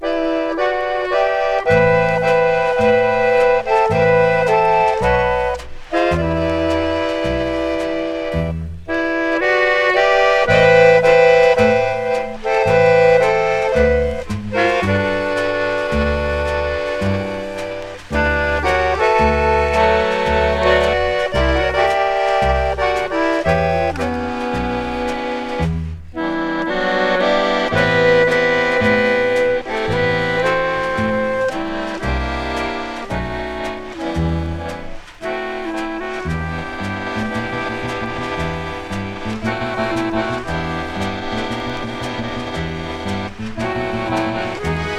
本盤は、活動の指針とも思えるビッグ・バンド・ジャズで聴かせます。
粋とも思えるムードがなんとも心地よく音から溢れ、艶やかな音、彩り豊かなアレンジも素敵。
Jazz, Big Band　USA　12inchレコード　33rpm　Mono